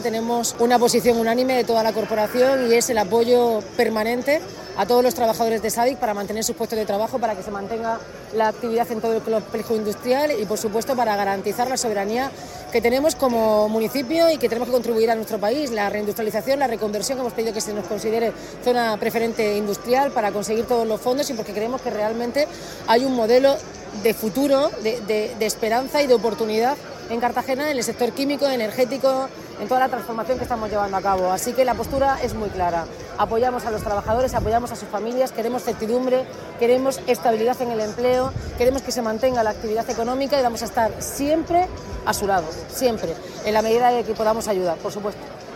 Enlace a Declaraciones de la alcaldesa Noelia Arroyo.
Trabajadores de la planta de SABIC en el complejo industrial de La Aljorra se han concentrado este viernes en la plaza del Ayuntamiento de Cartagena dentro del calendario de movilizaciones que están desarrollando para reclamar garantías sobre la continuidad de la actividad industrial y la estabilidad de sus puestos de trabajo, unos 2.500 de la empresa auxiliar y más de 500 directos